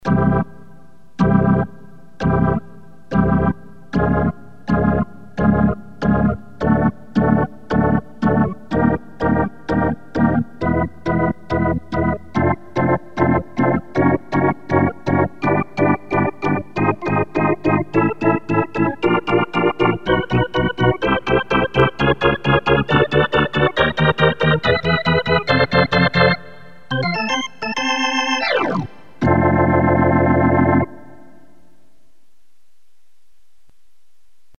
Organ Slow Climb
Tags: Princeton Hockey Baker Rink